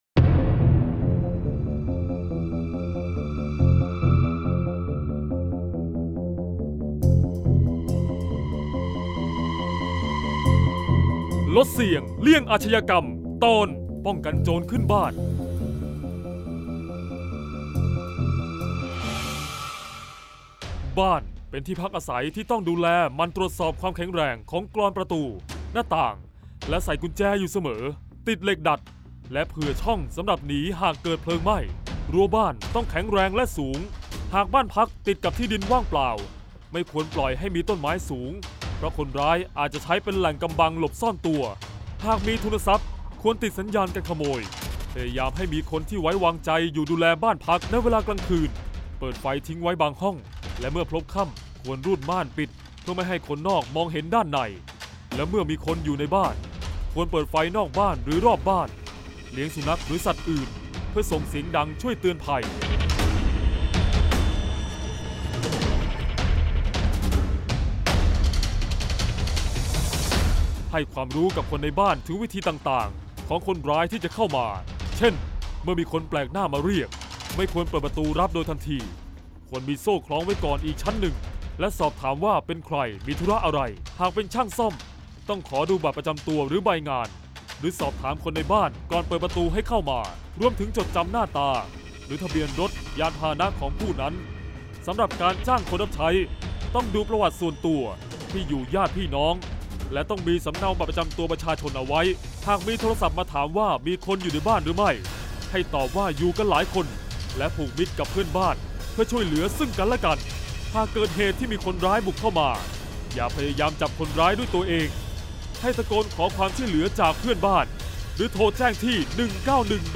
เสียงบรรยาย ลดเสี่ยงเลี่ยงอาชญากรรม 43-ป้องกันโจรขึ้นบ้าน